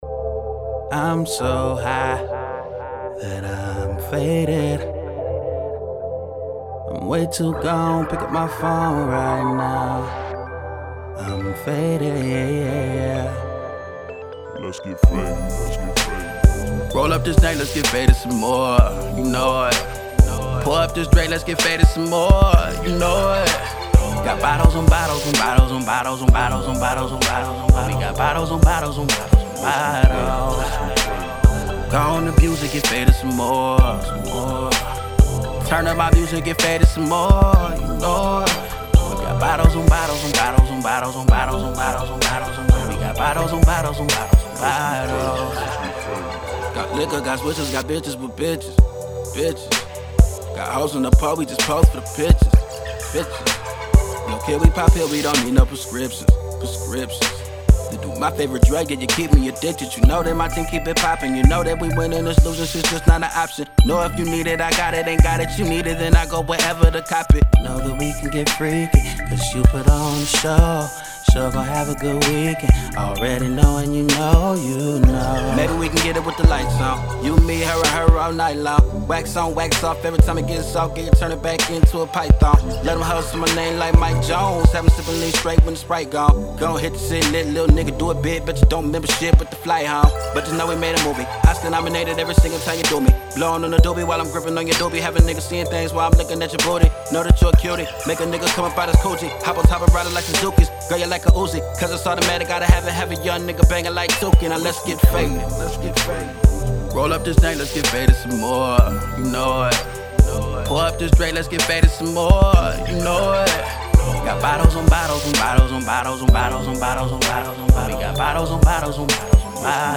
Hiphop
dope beat